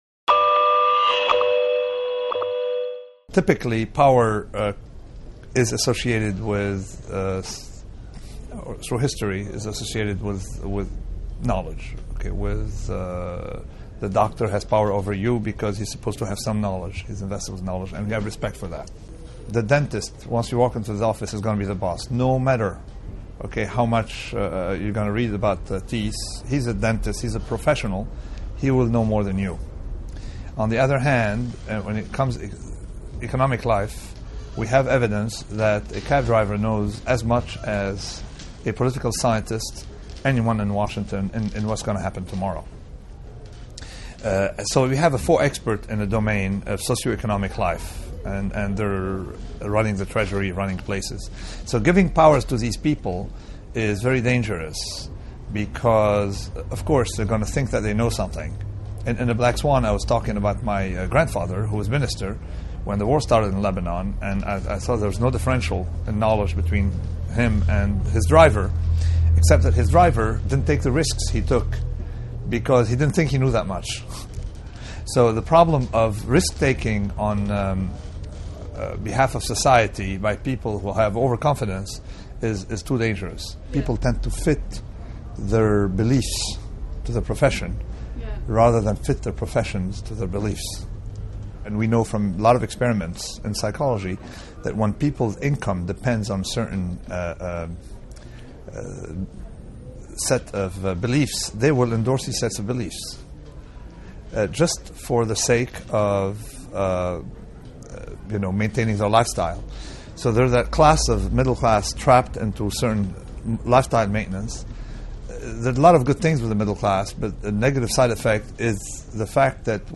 Nassim Taleb Hay Festival 2010 Guardian Video Clip
Nassim-Taleb-Guardian-Hay-Festival-2010.mp3